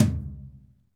TOM RLTOM0GR.wav